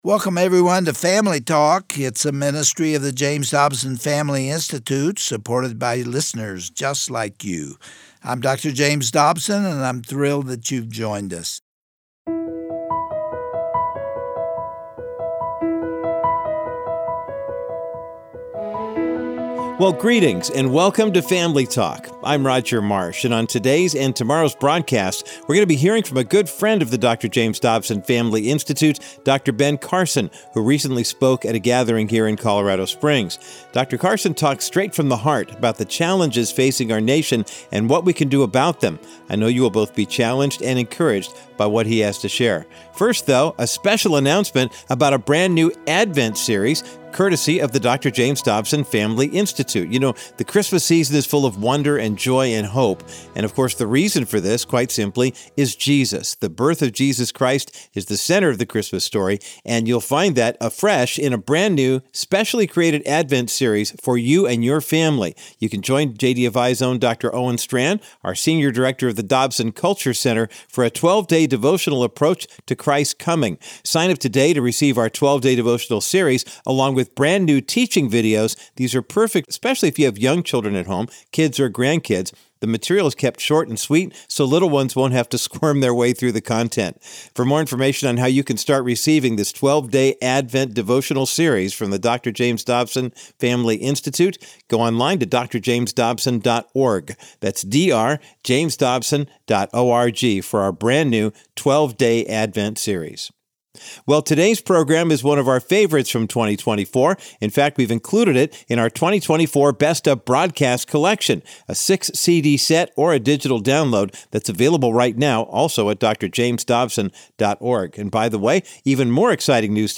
On today’s edition of Family Talk, Dr. Ben Carson gives a stirring speech about our society today, and the responsibilities that Christians have been given by our Father in Heaven. Let your heart be inspired as Dr. Carson reminds us that we are all fearfully and wonderfully made in God’s image.